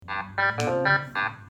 Звуки уведомлений Samsung